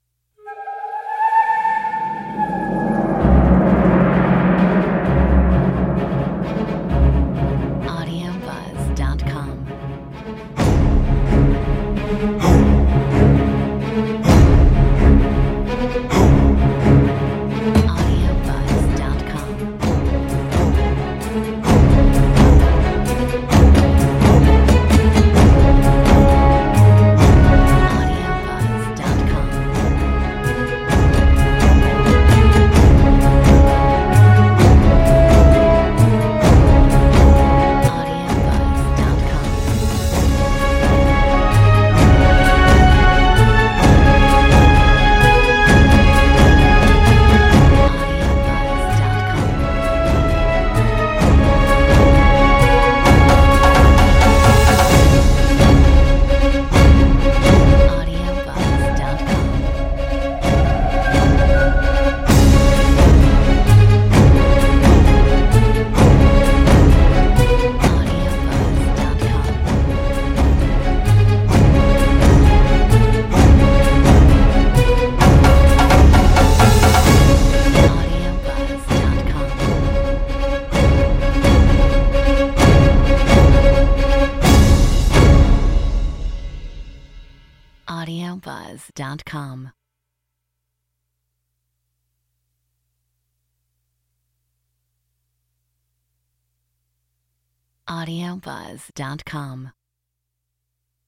Metronome 130 BPM